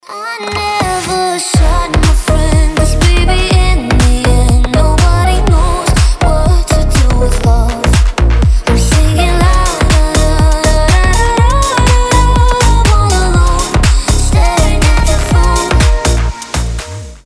• Качество: 320, Stereo
красивый женский голос
гудки